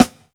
SNARE_BETRAYED.wav